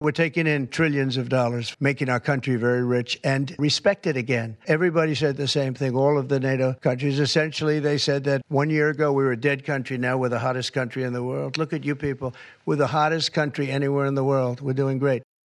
President Trump says the tariffs are a great move for the US: